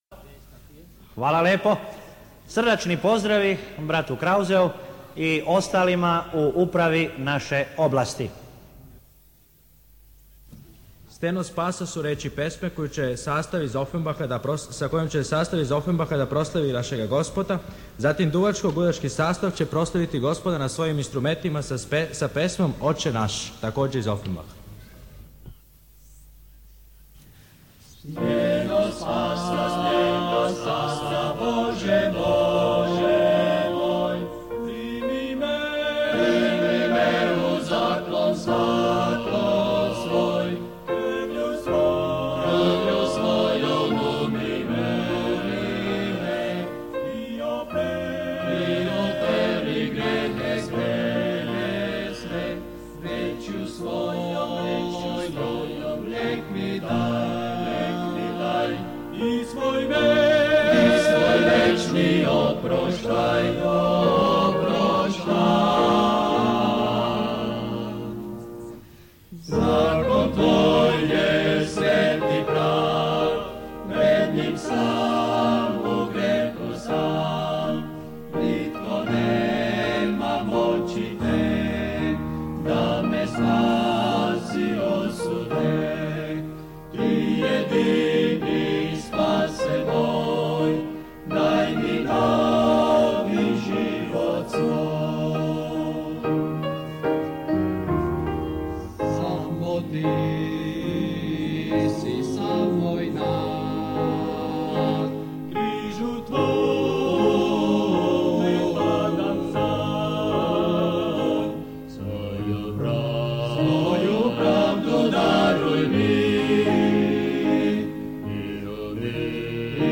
on 2010-01-03 - Steno Spasa, Boze moj - istorijski snimak 1978